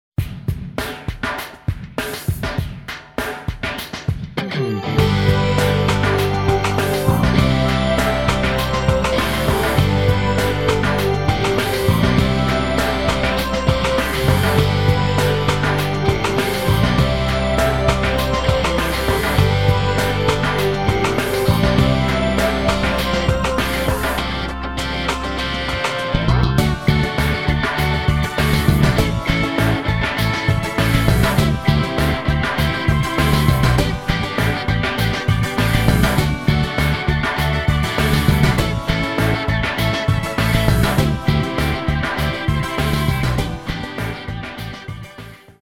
Genre: Rockpop
Stereo